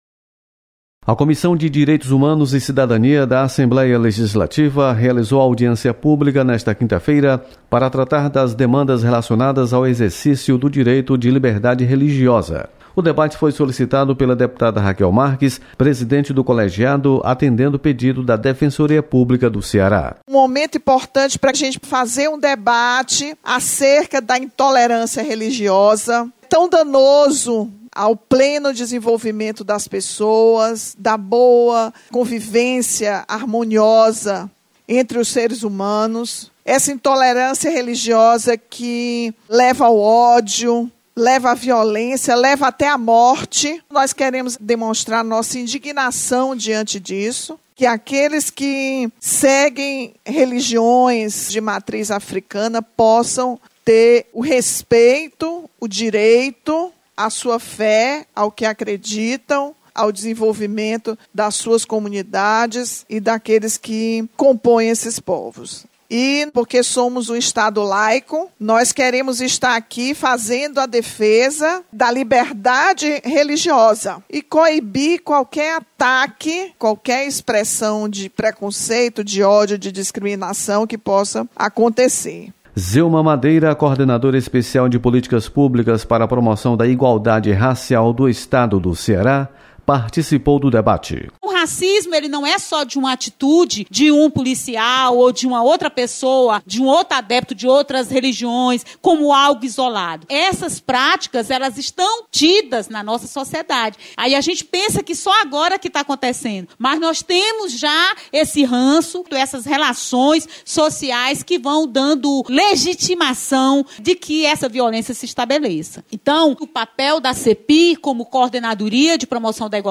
Audiência